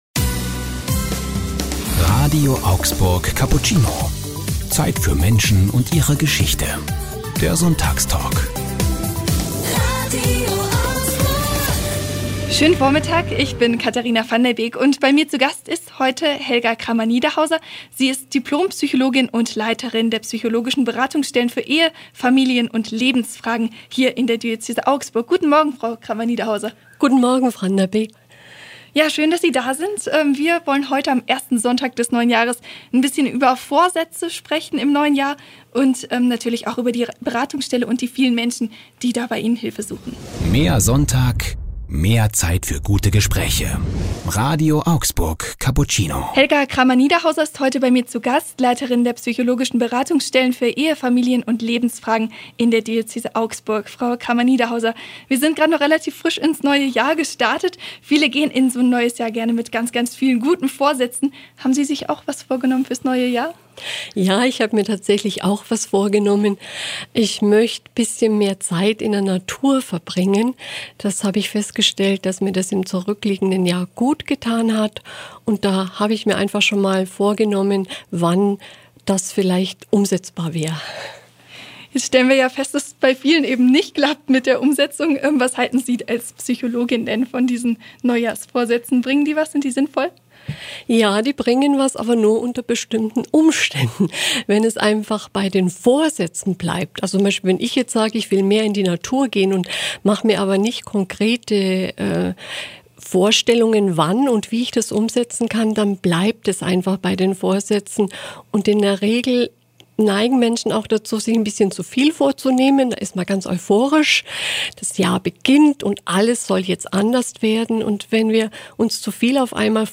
Hier können Sie den Sonntagstalk nachhören: Mehr